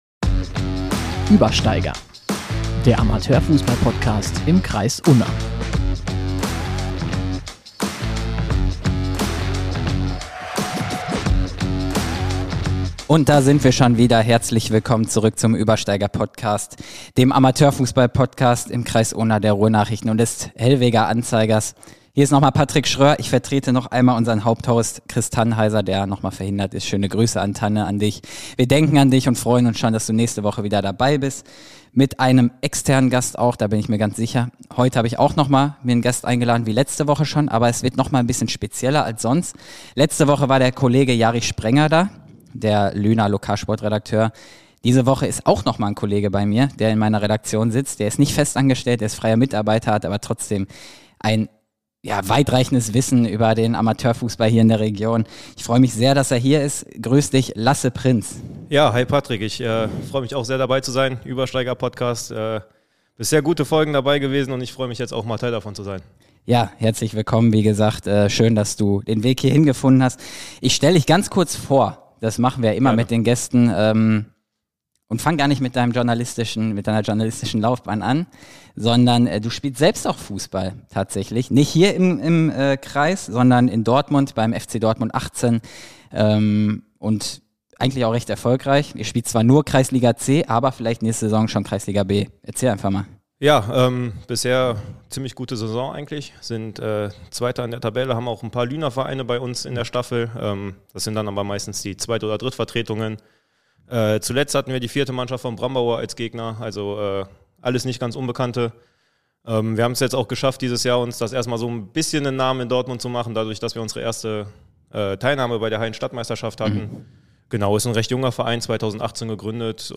Wer wird Meister in der Kreisliga A2 Unna-Hamm? Die beiden Sportjournalisten